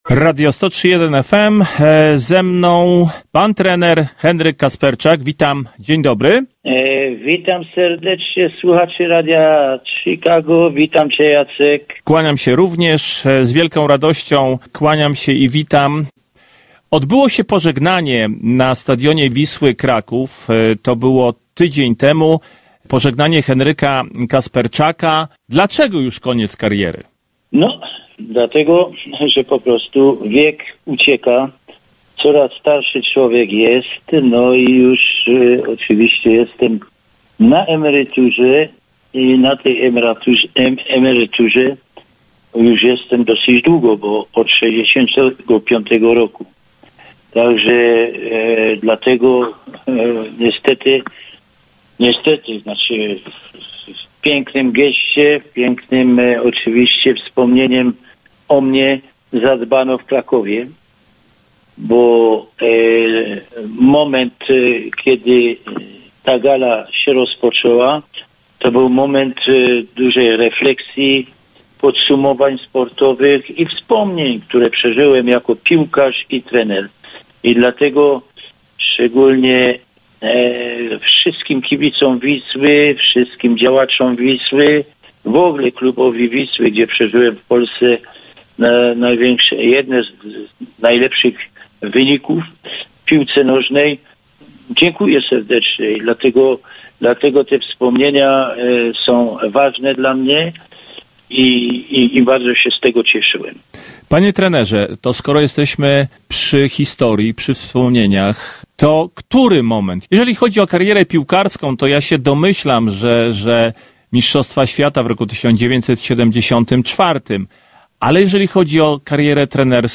Wywiad zarejestrowany 08/03/25